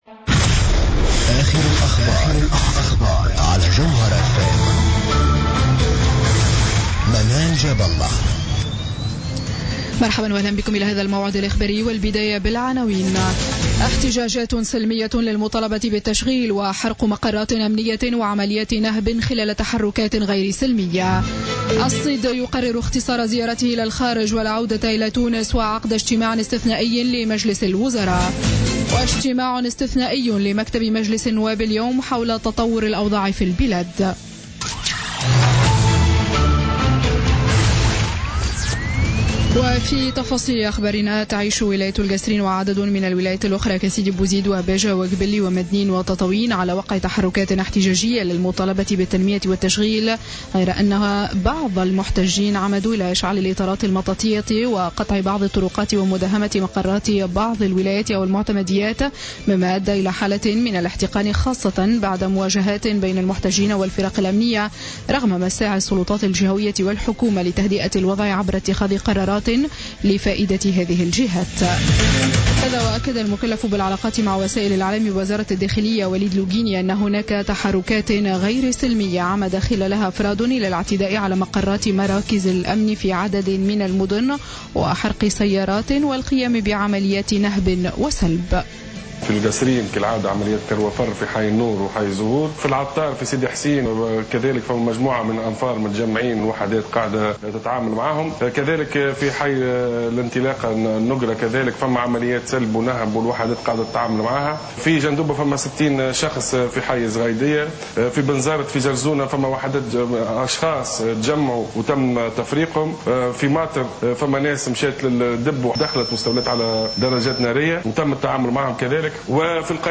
نشرة أخبار منتصف الليل ليوم الجمعة 22 جانفي 2016